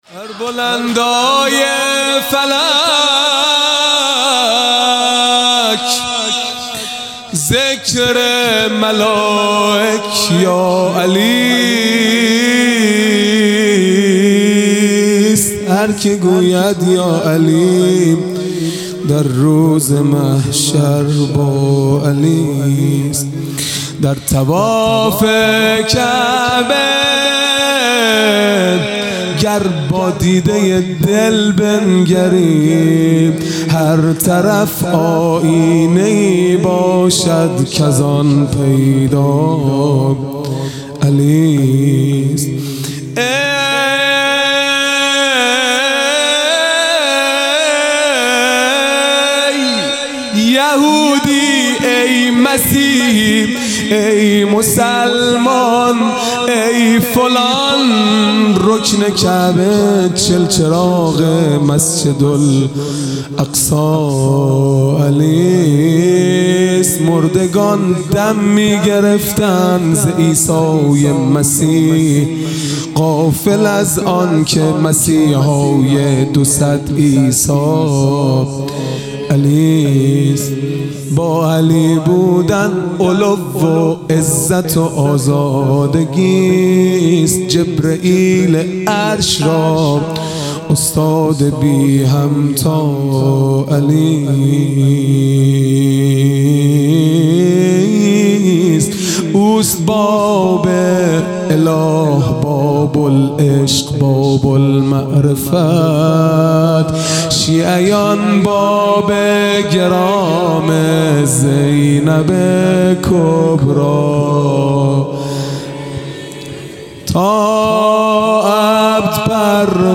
خیمه گاه - هیئت بچه های فاطمه (س) - مناجات پایانی | بر بلندای فلک، ذکر ملائک یاعلیست
محرم ۱۴۴۱ | شب هشتم